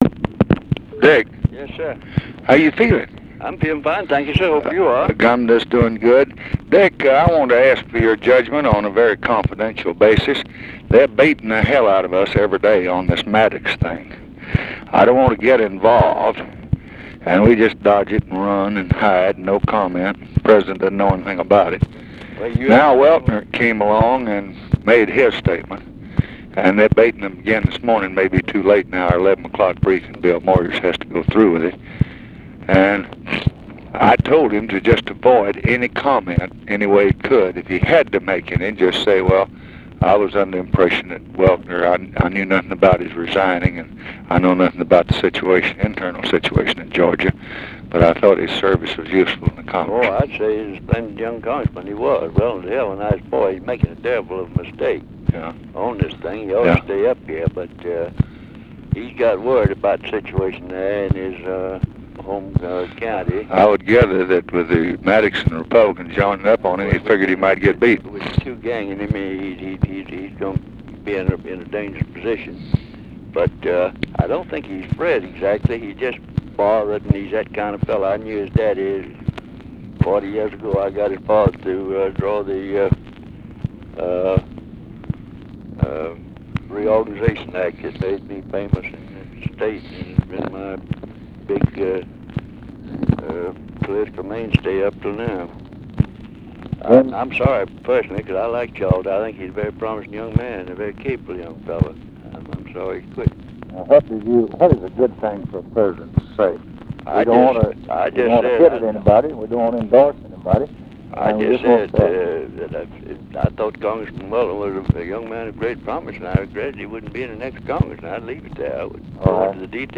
Conversation with RICHARD RUSSELL and JAKE JACOBSEN, October 4, 1966
Secret White House Tapes